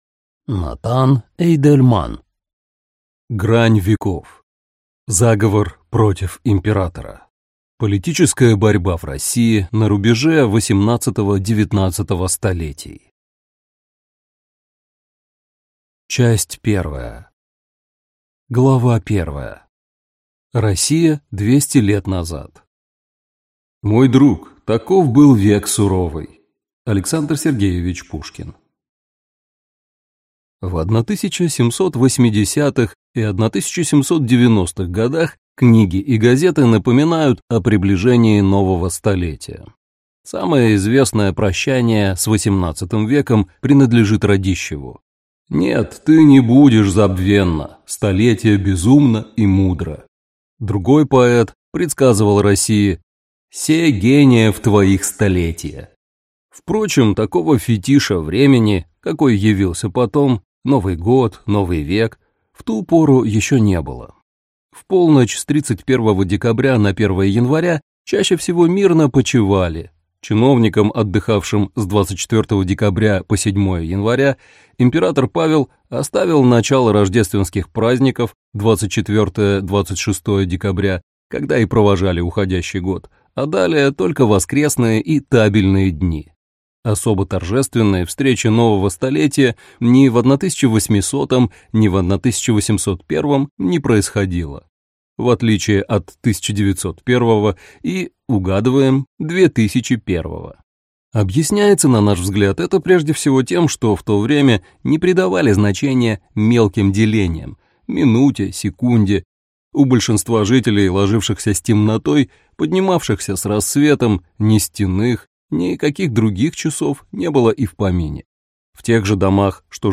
Аудиокнига Грань веков. Заговор против императора. Политическая борьба в России на рубеже XVIII–XIX столетий | Библиотека аудиокниг